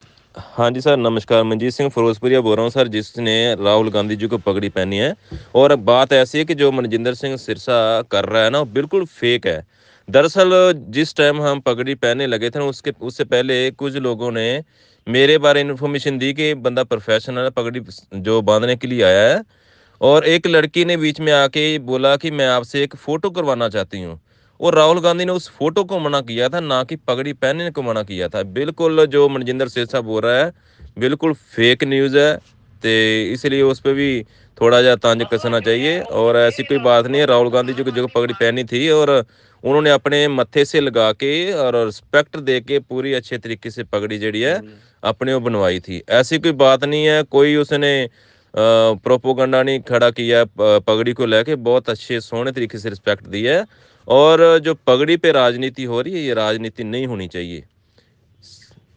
उन्होंने हमें अपने बयान का रिकॉर्डिंग भी भेजा जिसमें वो भाजपा नेता मजिंदर सिंह सिरसा द्वारा राहुल गांधी पर लगाए गए आरोप को सिरे से खारिज कर रहे हैं.